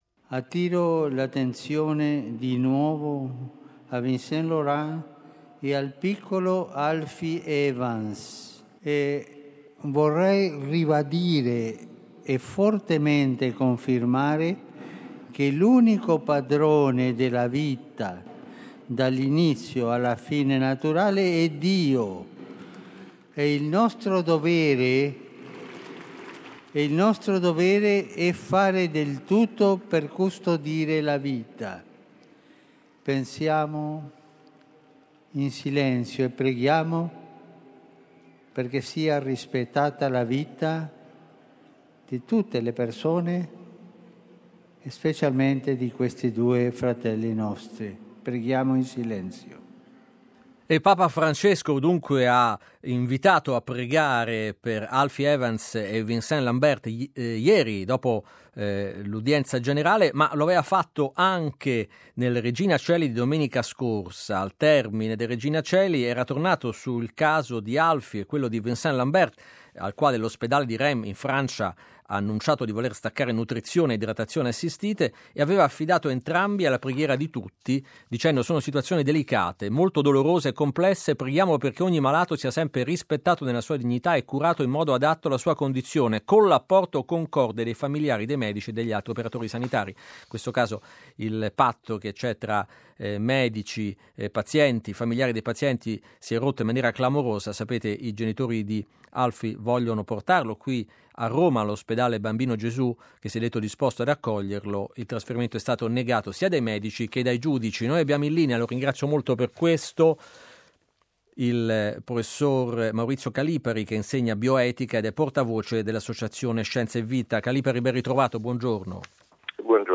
intervista a Radio in Blu